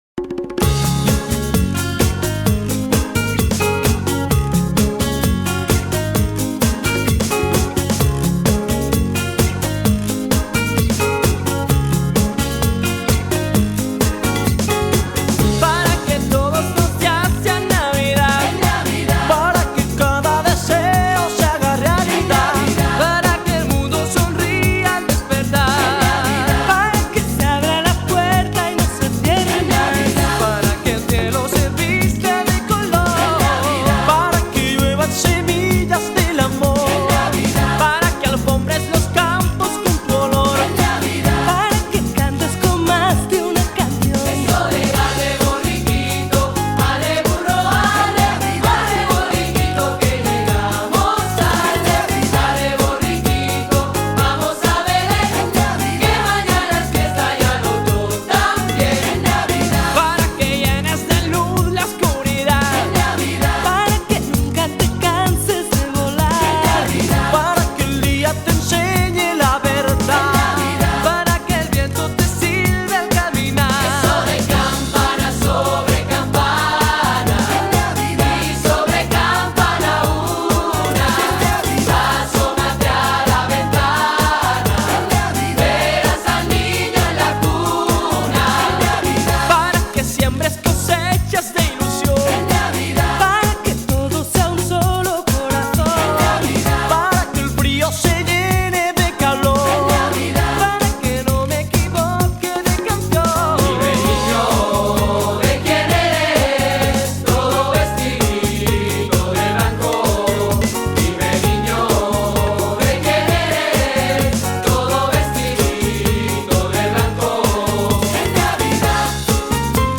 NO és la instrumental, així assajareu millor.